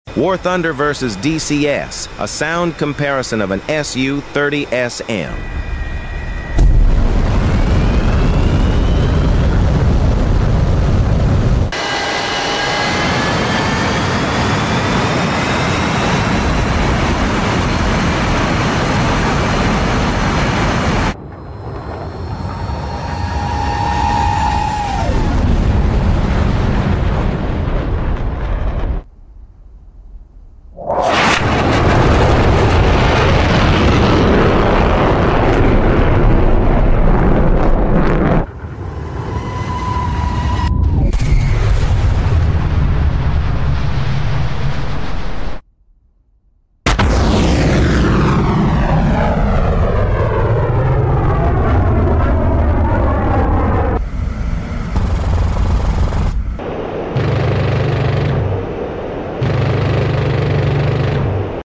Su30SM Sound comparson between DCS sound effects free download
Su30SM Sound comparson between DCS and Warthunder!